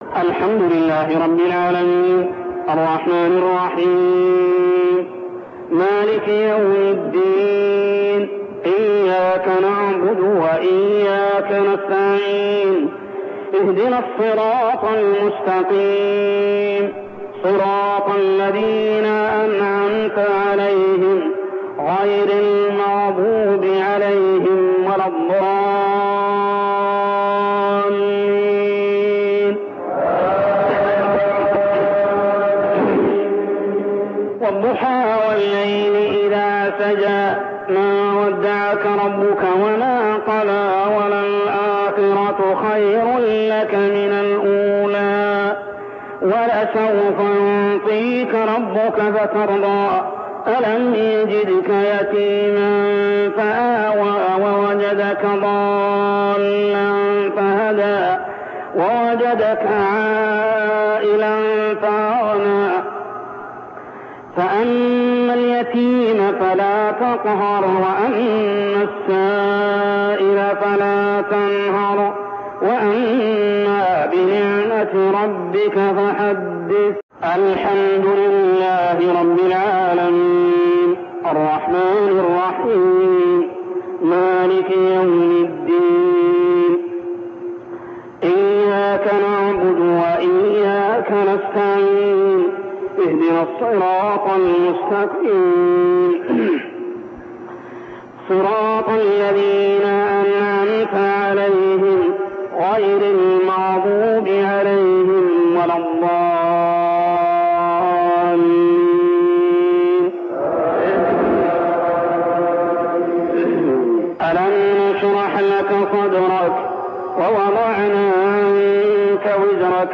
تلاوة من صلاة المغرب لسورتي الضحى و الشرح كاملة عام 1402هـ | Isha prayer Surah Ad-duha and Ash-Sharh > 1402 🕋 > الفروض - تلاوات الحرمين